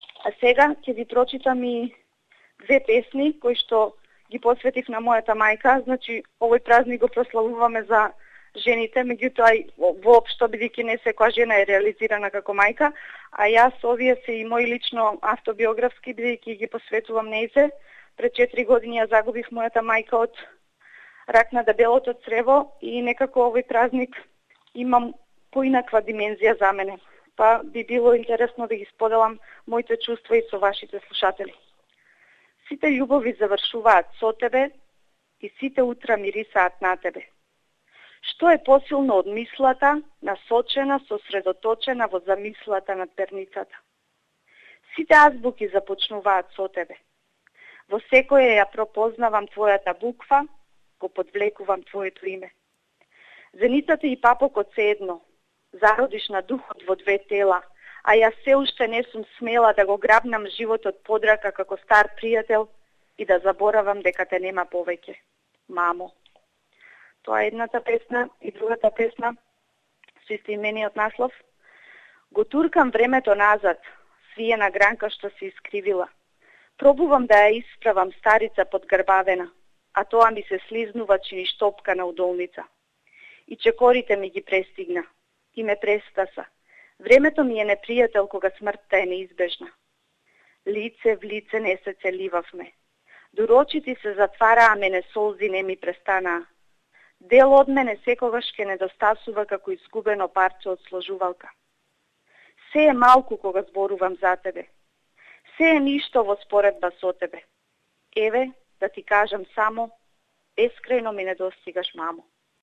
poet Source